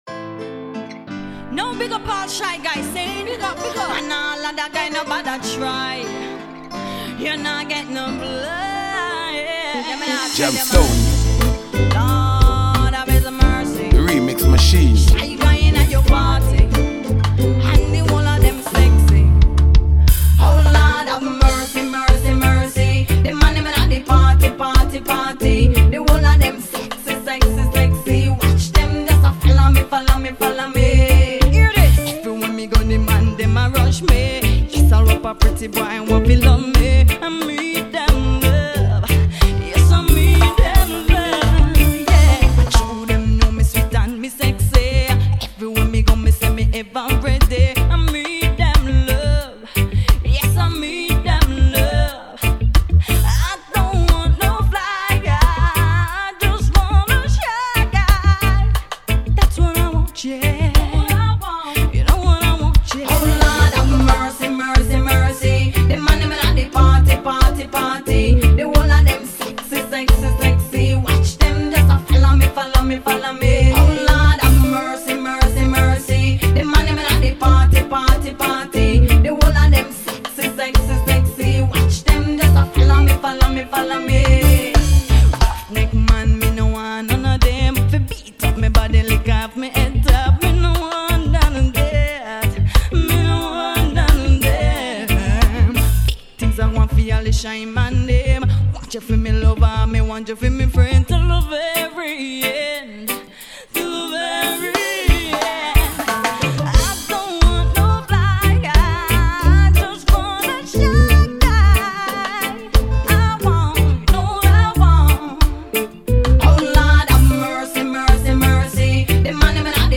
riddim